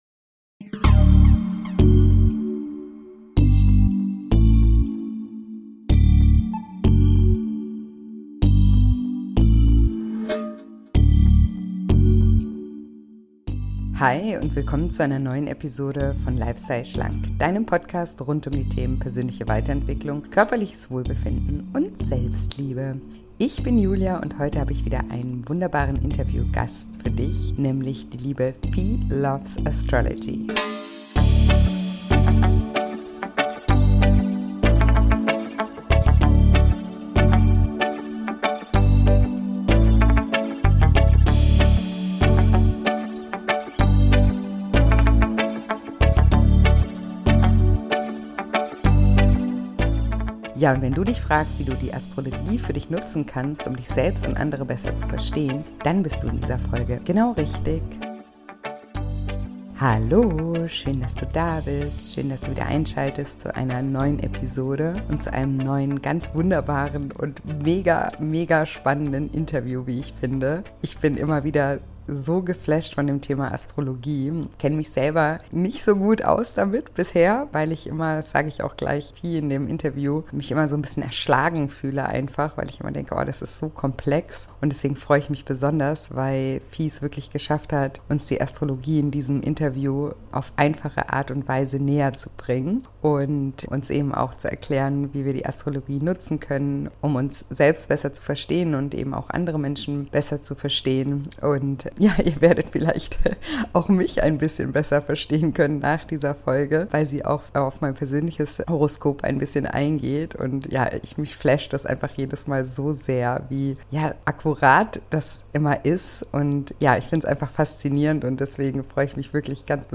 Ein mega spannendes und interessantes Interview, das du auf keinen Fall verpassen solltest.